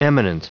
Prononciation du mot eminent en anglais (fichier audio)
Prononciation du mot : eminent
eminent.wav